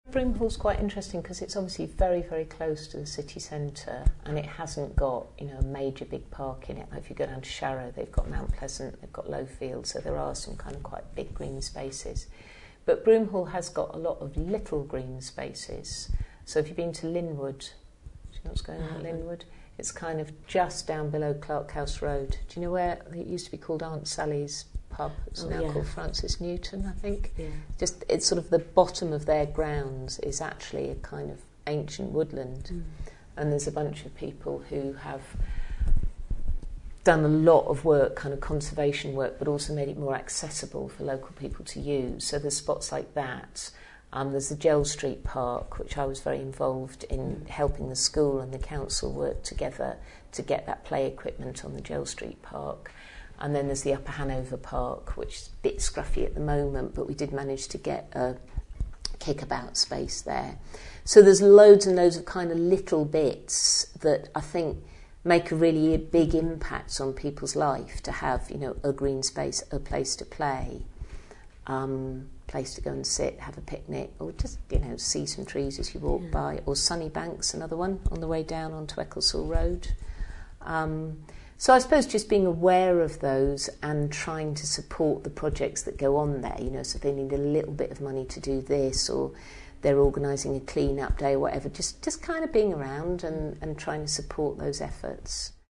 Local Councillor and Broomhall resident Jillian Creasy talks about some of the them below including the ancient woodland at Lynwood Gardens, Gell St playground and the Sunnybank nature reserve.
Jillian Creasy discusses Broomhall's green spaces